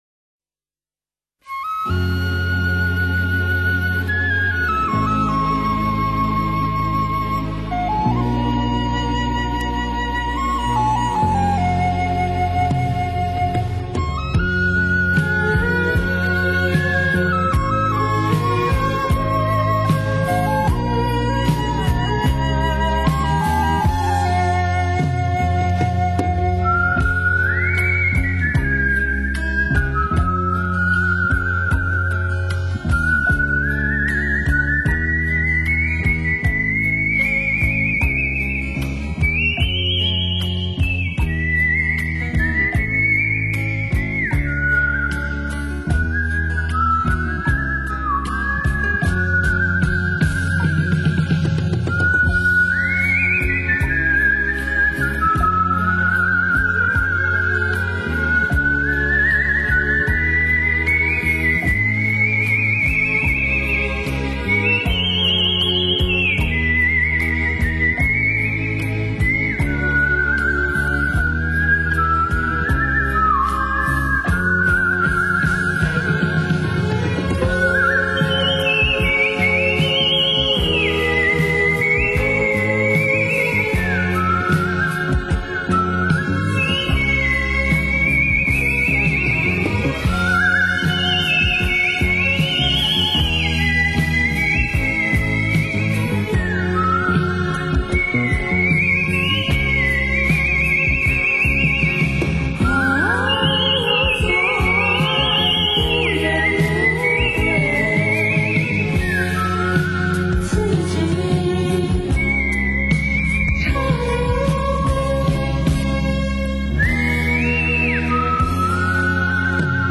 浪漫口哨音乐
天蓝蓝，云轻轻；轻快的口哨是如影相随的小精灵，每个音符都充满了动人心弦的优美韵律，令心灵轻松无比，随风飘逸……